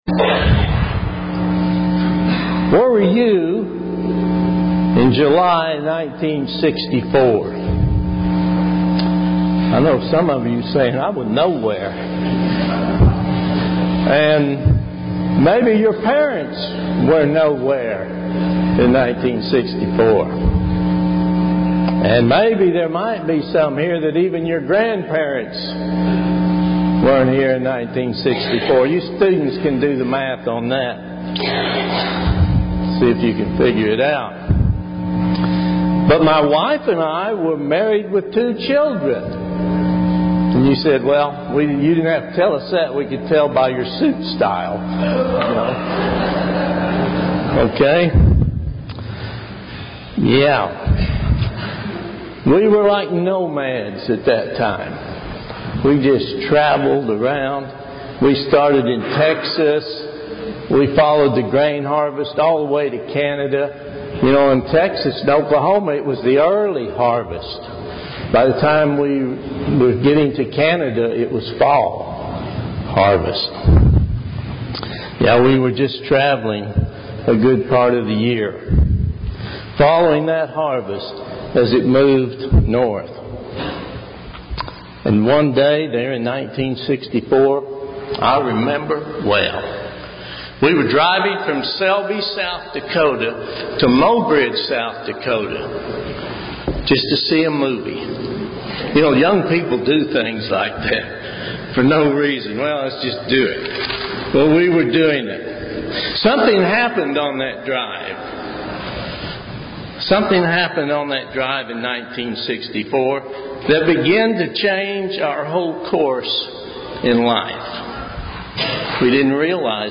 When God calls us, what are some of our responsibilities we need to do in order to grow in the power of the Holy Spirit? This sermon was given on Pentecost.
Given in Oklahoma City, OK